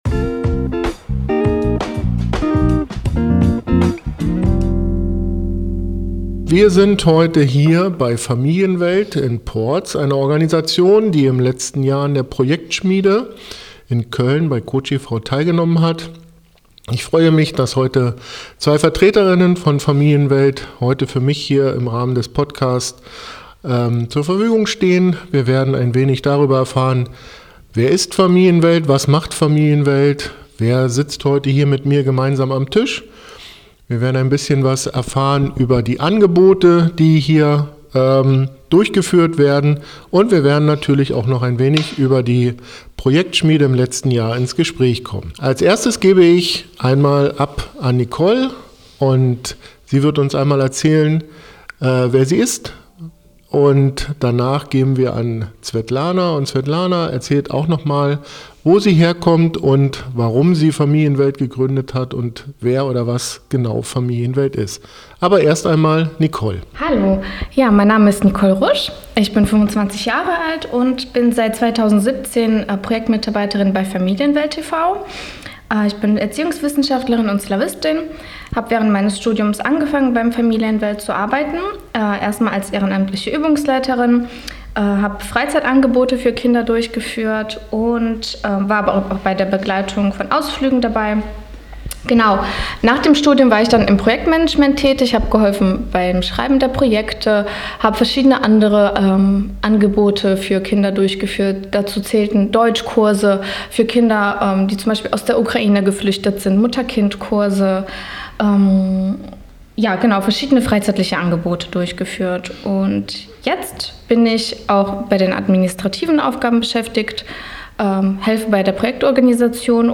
Das Interview